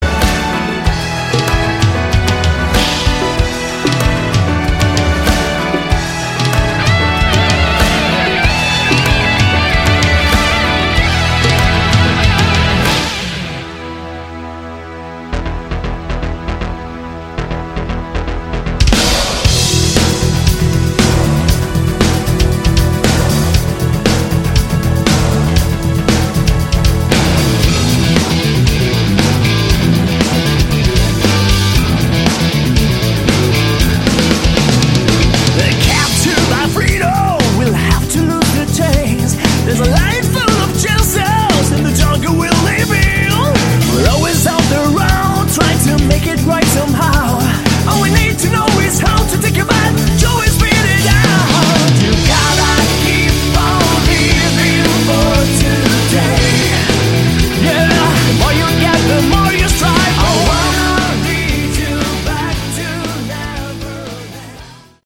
Category: Melodic Rock / AOR